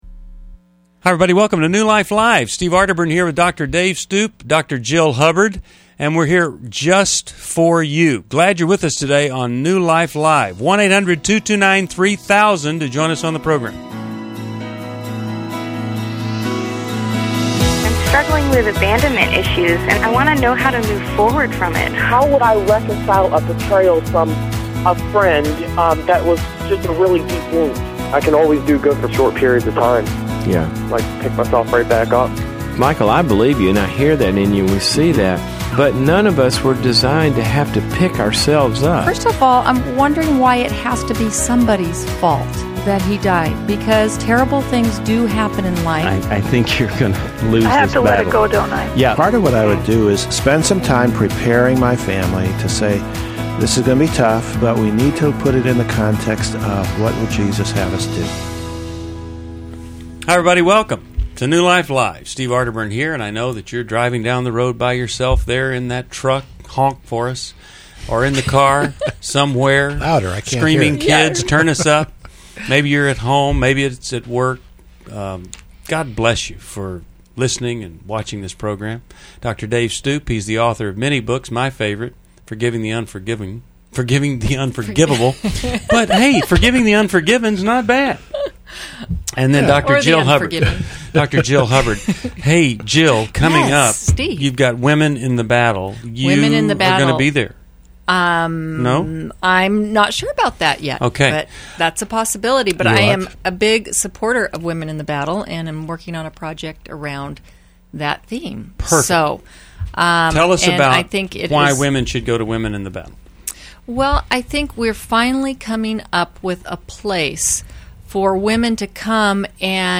Explore self-worth, grief, and relationships in New Life Live: August 19, 2011, as experts address caller struggles with suicide, loss, and narcissism.